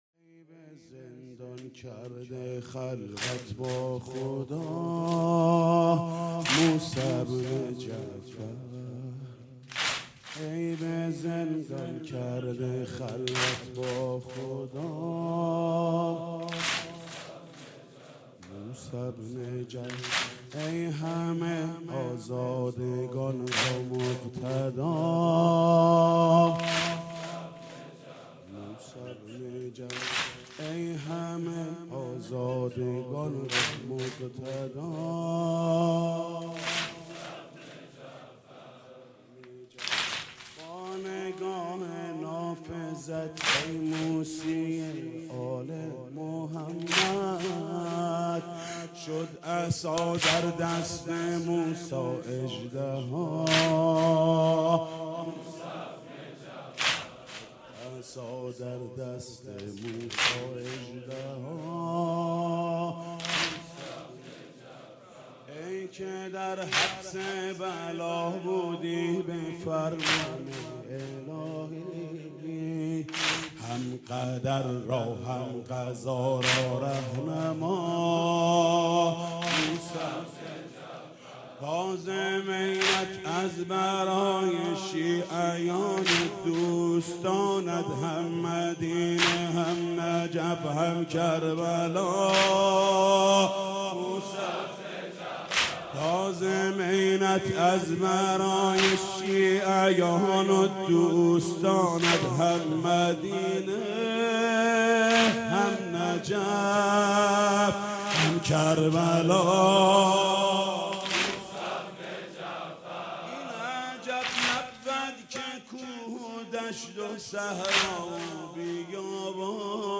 مداحی حاج محمود کریمی به مناسبت شهادت امام موسی کاظم(ع)